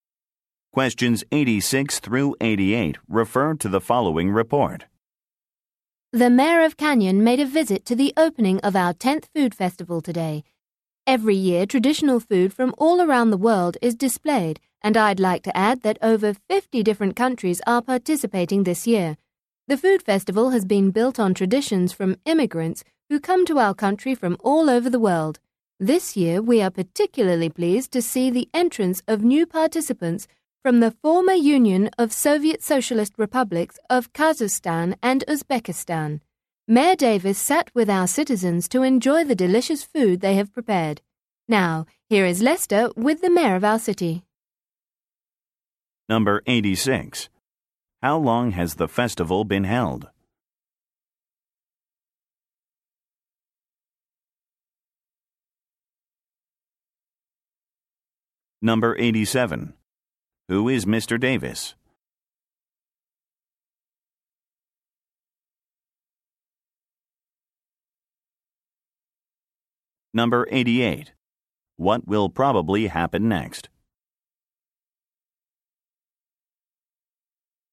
86-88 refer to the following report.